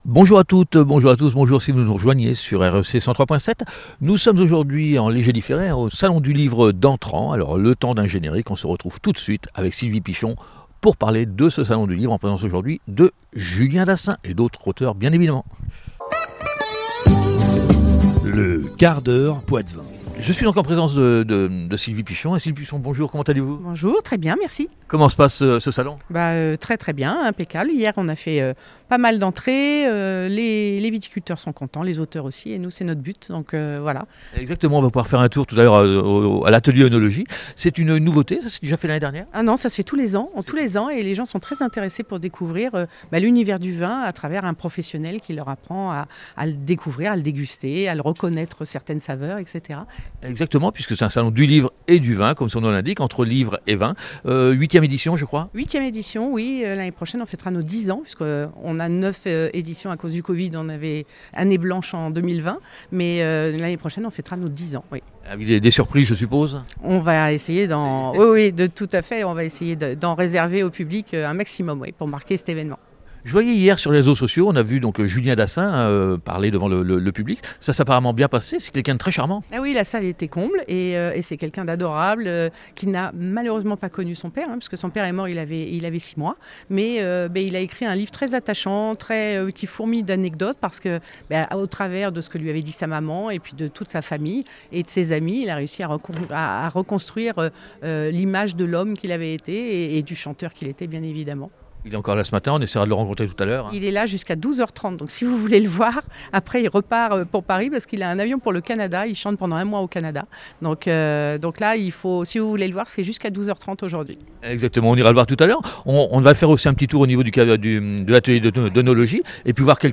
Antr’Livres et Vins, petite ballade au coeur de l’un des plus beaux salons de la Vienne lors de son édition 2025